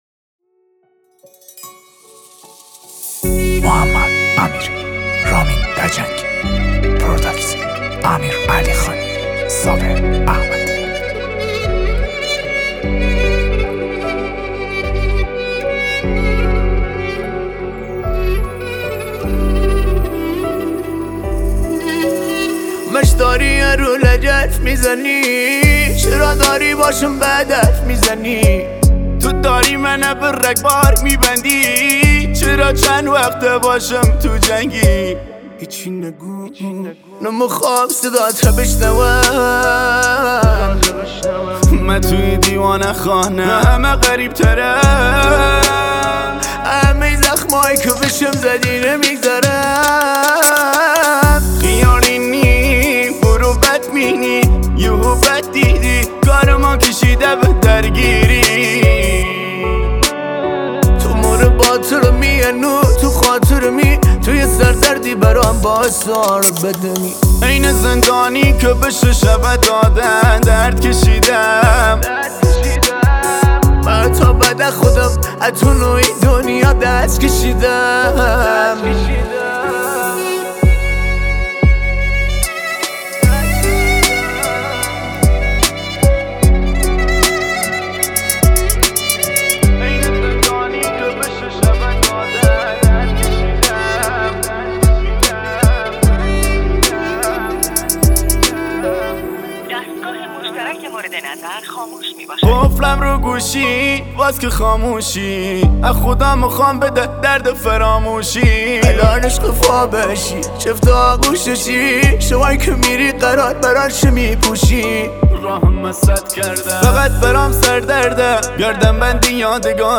موزیک کردی